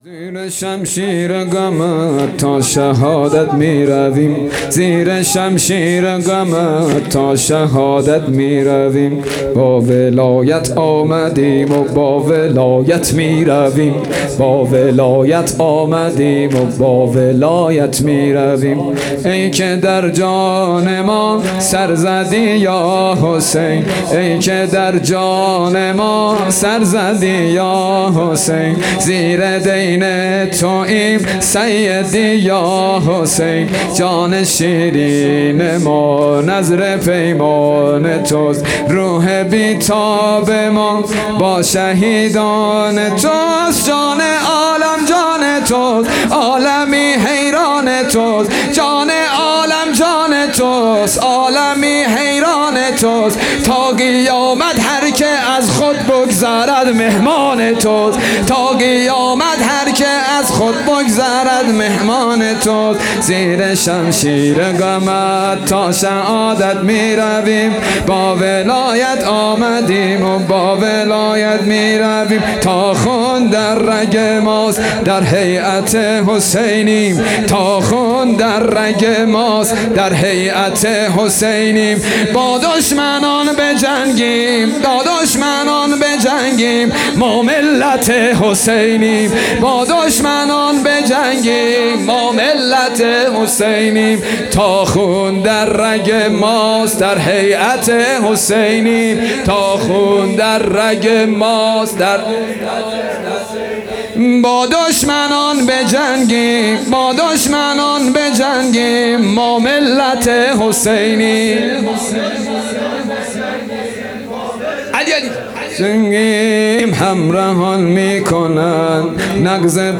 حسینیه بیت النبی - شب چهارم محرم الحرام 1442